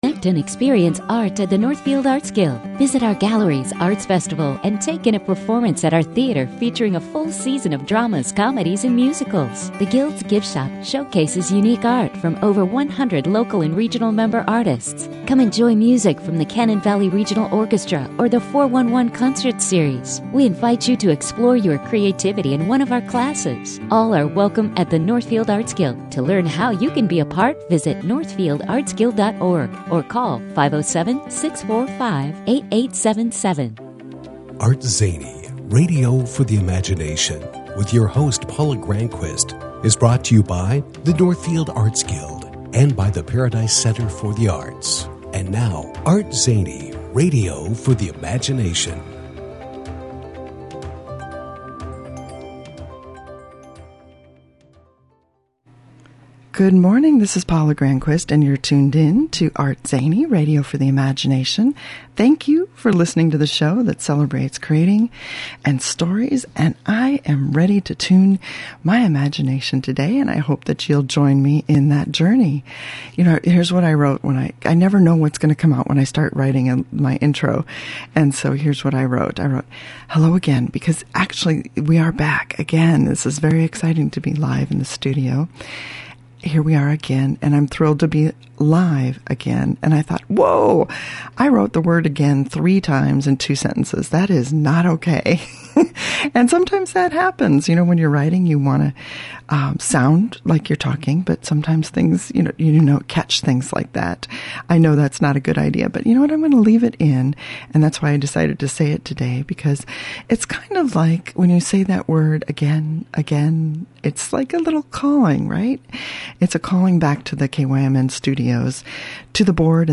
Today in the ArtZany Radio studio